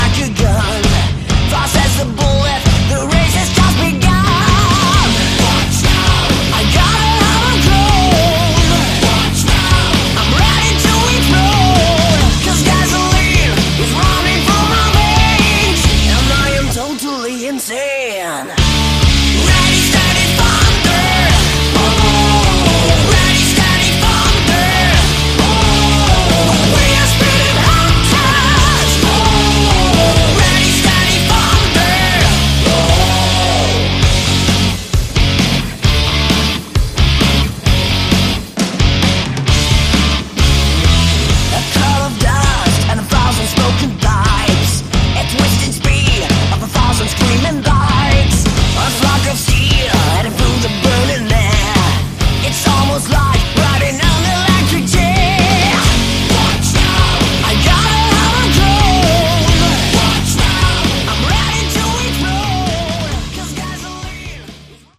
Category: Melodic Metal